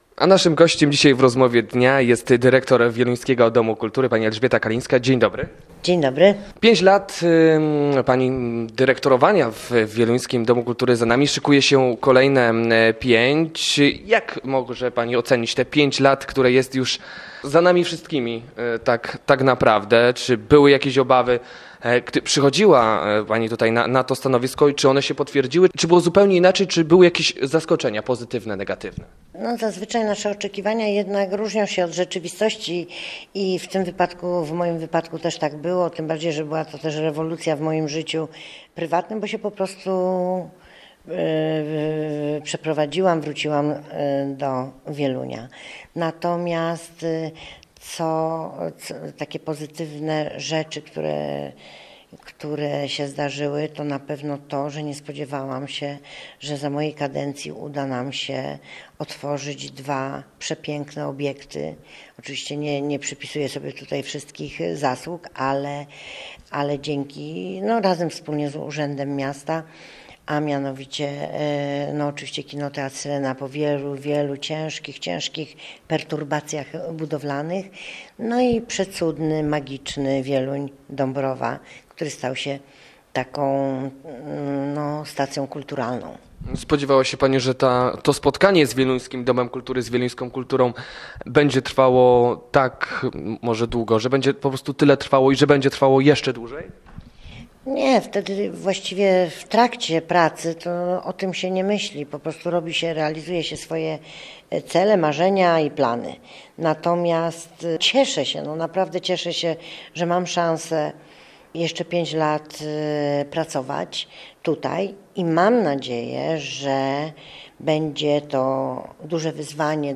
Rozmowa Dnia audio